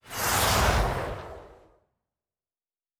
Weapon 11 Shoot 3 (Rocket Launcher).wav